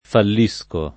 fallisco [ fall &S ko ], ‑sci